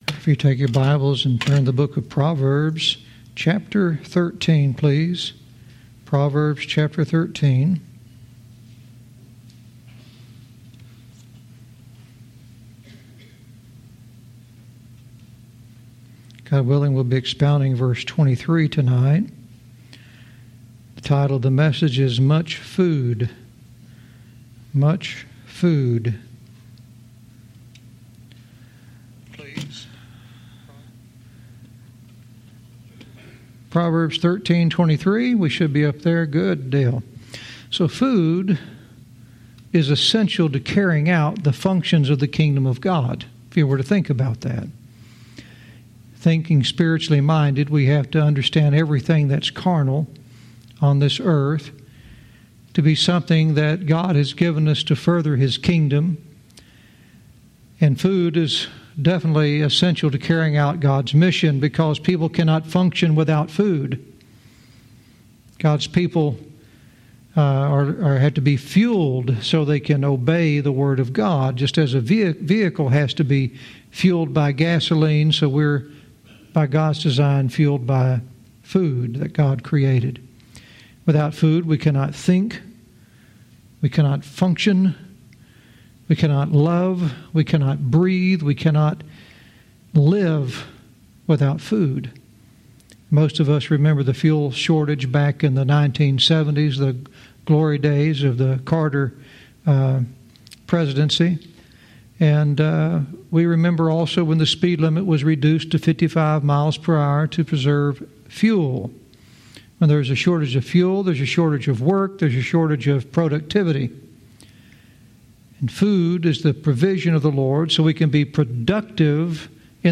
Verse by verse teaching - Proverbs 13:23 "Much Food"